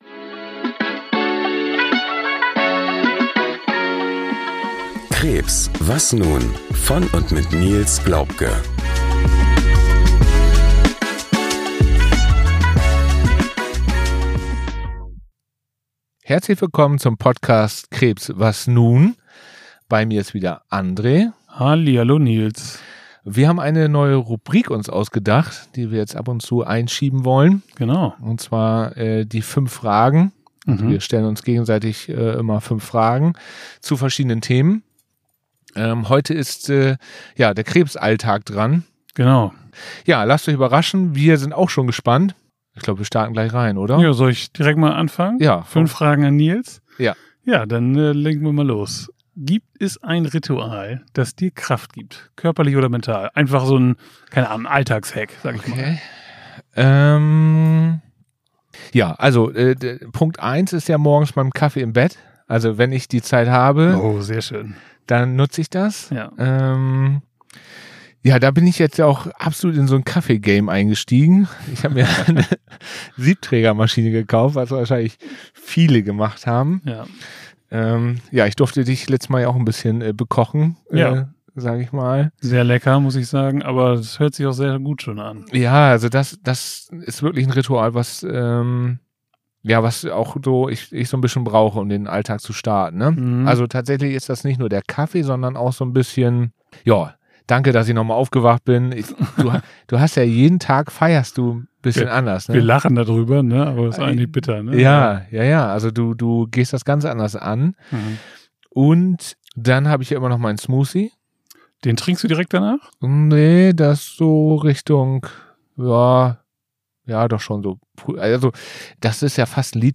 Beschreibung vor 9 Monaten In Folge 57 wird’s persönlich: Wir stellen uns gegenseitig fünf Fragen zum Leben mit Krebs – offen, ehrlich, ungeschönt.